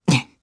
Fluss-Vox_Happy1_jp.wav